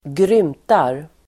Uttal: [²gr'ym:tar]